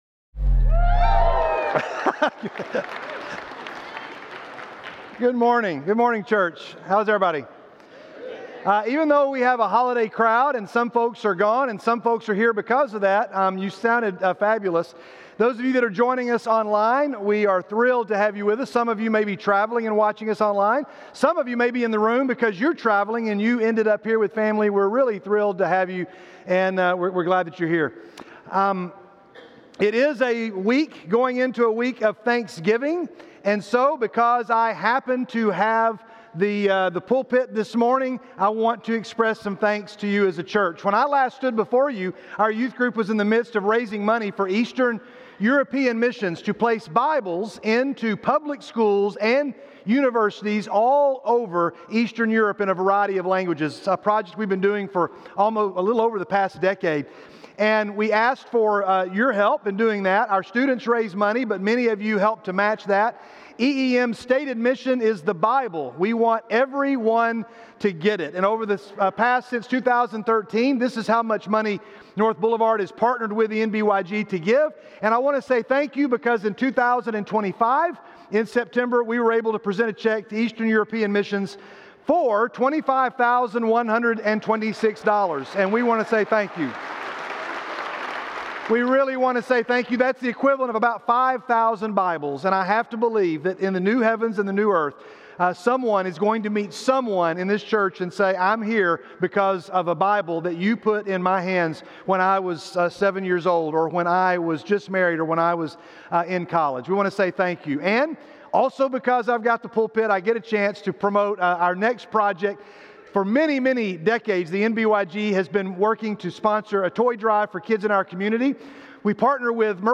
Title 00:00 / 01:04 All Sermons SERMON AUDIO GATHERING Audio download audio download video Download Video Video Also on Be Kind.